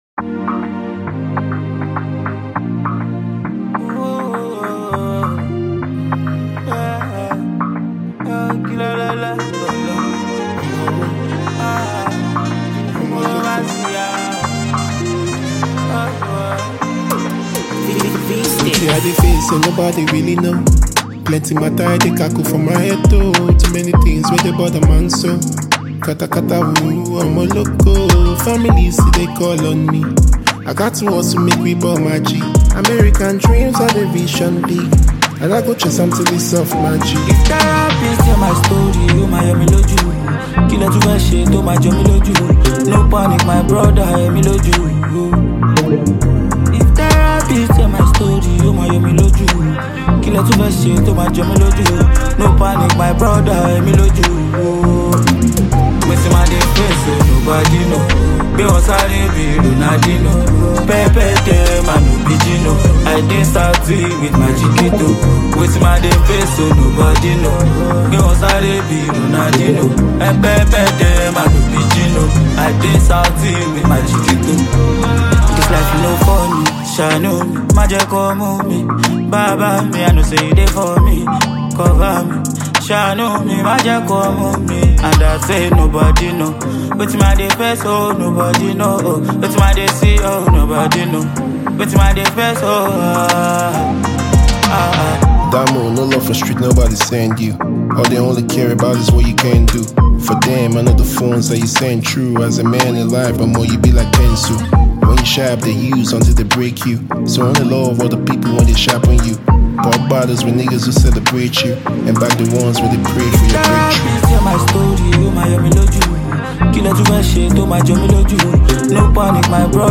Nigerian music artist
harmonious sound and style